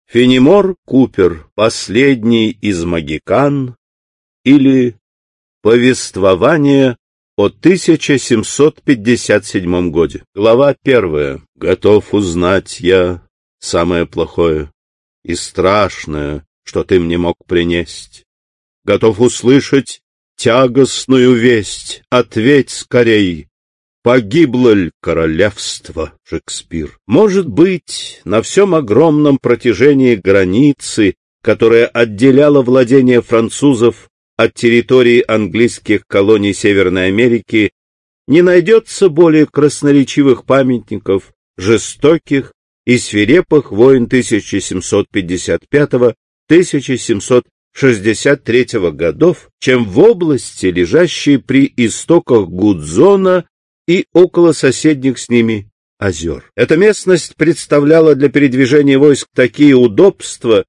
Аудиокнига Последний из могикан | Библиотека аудиокниг